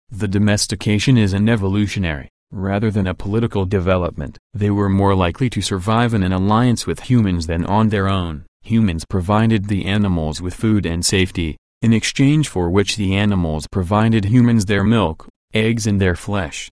Q25. Read Aloud - PTE